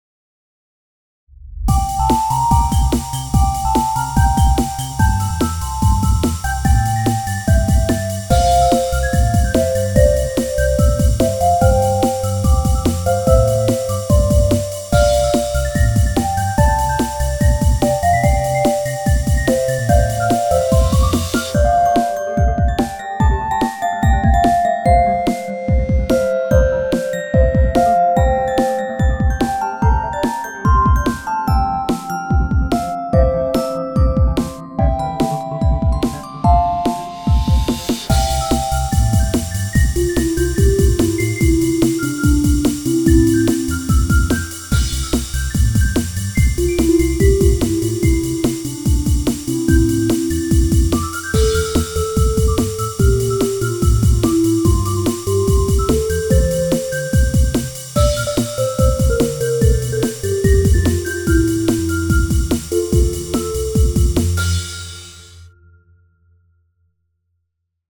アップテンポショート激しい